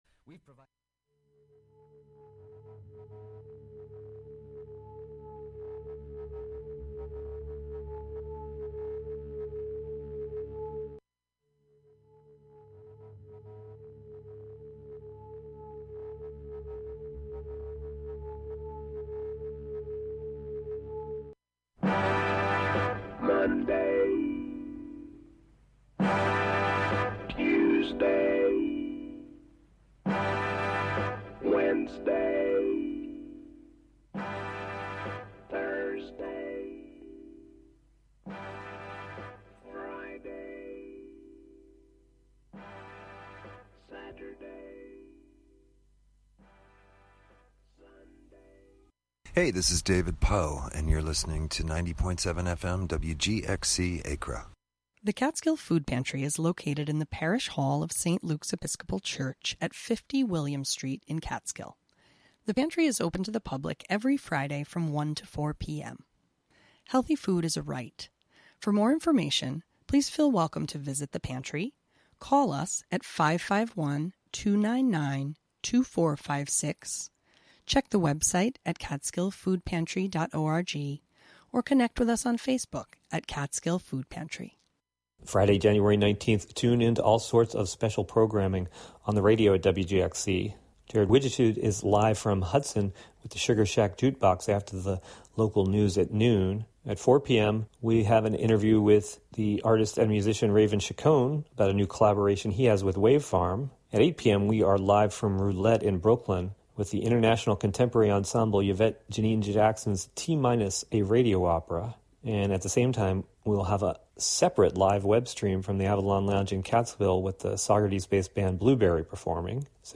Here we play back part of the conversation focused on his art, music, teaching, and the universe.